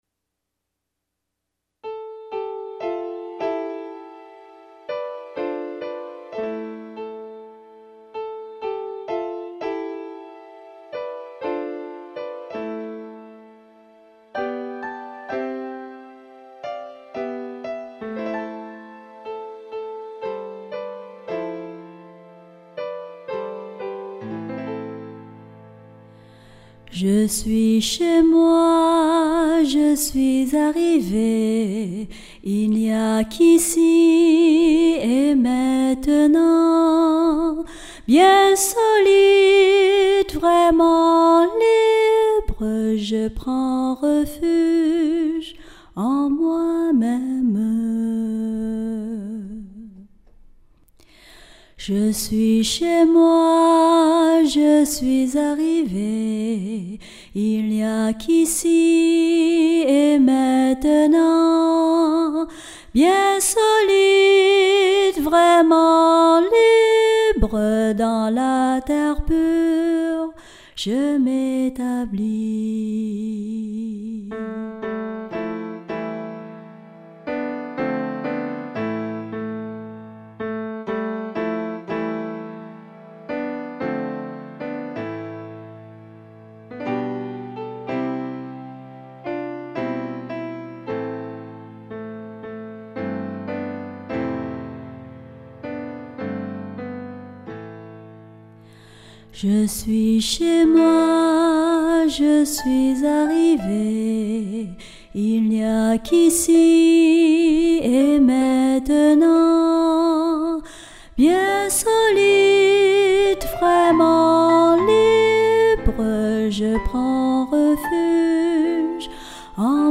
Chanson de pratique classique du Village des Pruniers